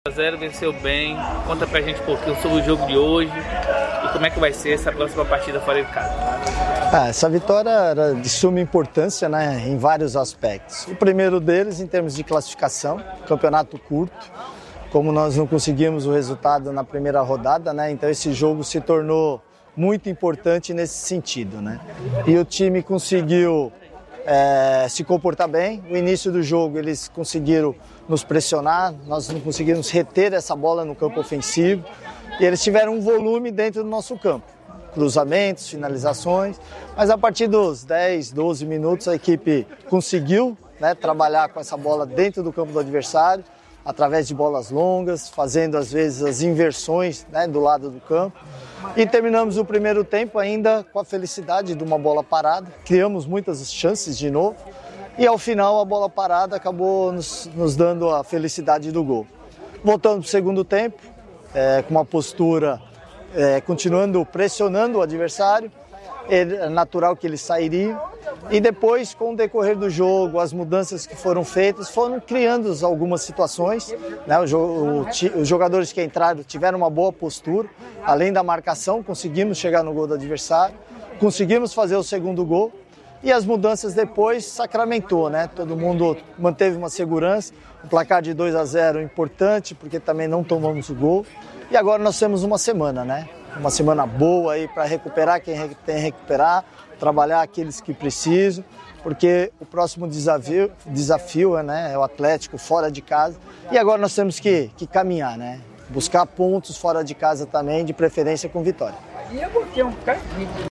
Sonoras Pós Jogo, Jequié x Colo-Colo